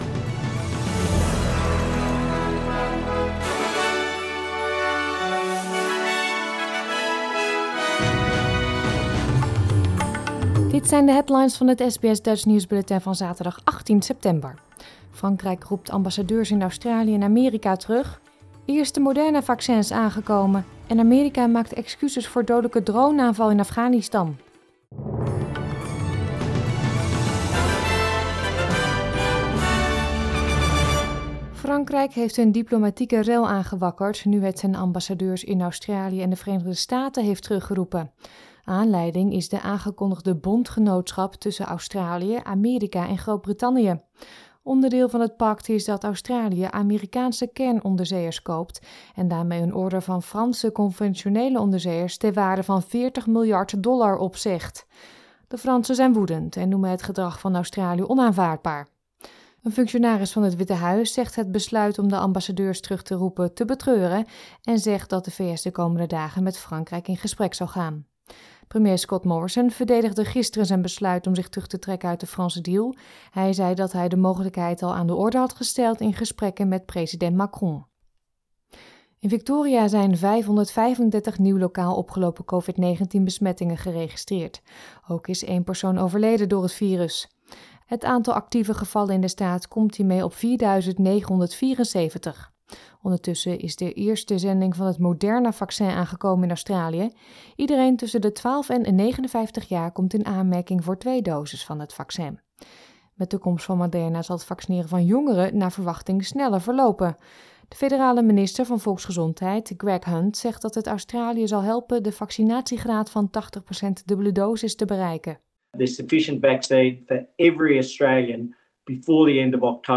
Nederlands/Australisch SBS Dutch nieuwsbulletin van zaterdag 18 september 2021